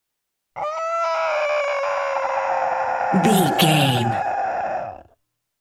Monster pain growl little creature
Sound Effects
Atonal
scary
ominous
angry